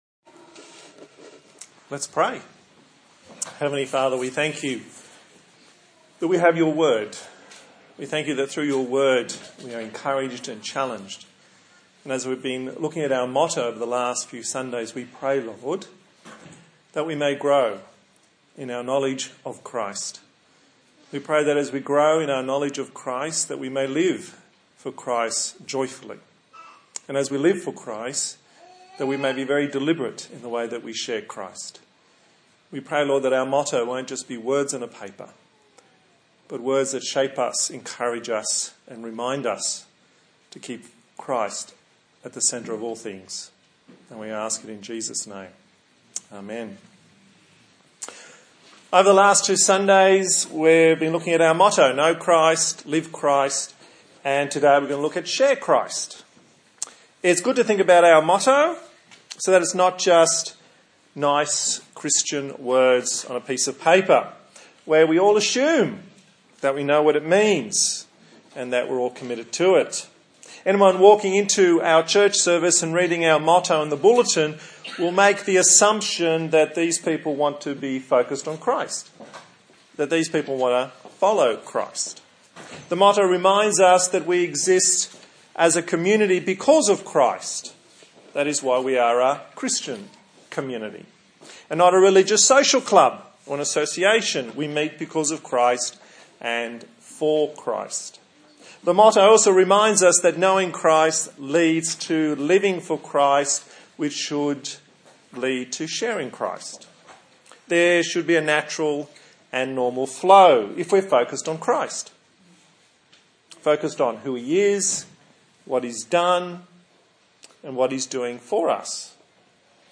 A sermon on the motto of TPC, "Share Christ"
1 Corinthians 9:19-23 Service Type: Sunday Morning A sermon on the motto of TPC